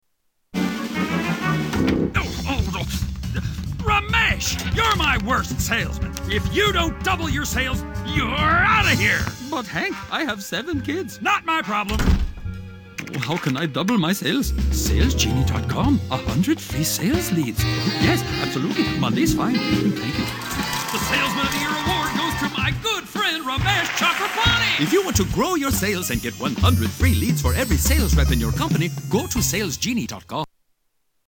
Tags: Television Awful Commericals Bad Commercials Commercials Funny